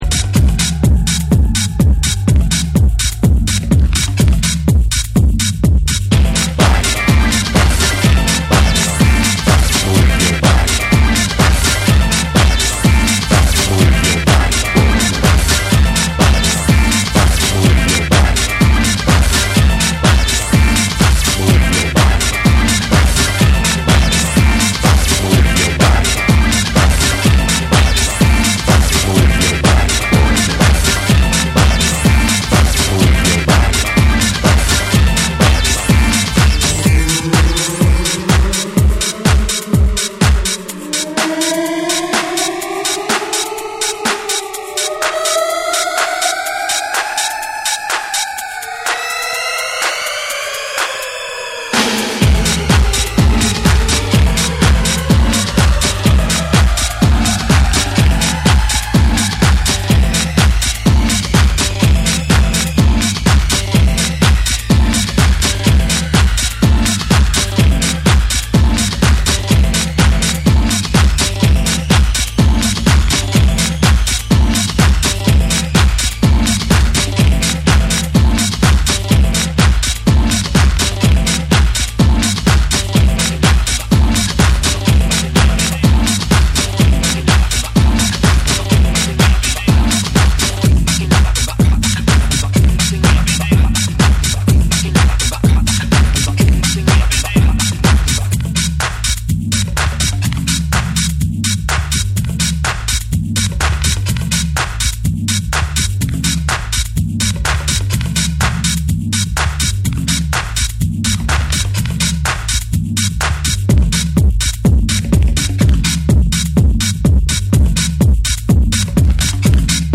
ソウルフルなヴォーカルとグルーヴィーなビートが完璧に絡み合う、永遠のフロア・アンセム！
TECHNO & HOUSE